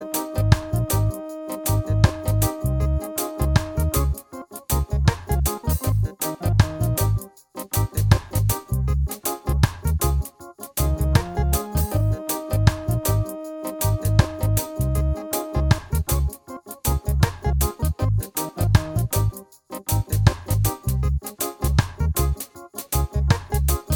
minus guitars no Backing Vocals Reggae 4:09 Buy £1.50